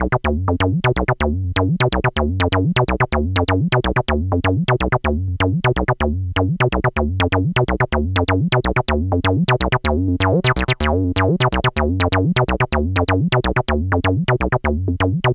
cch_acid_loop_bouncer_125.wav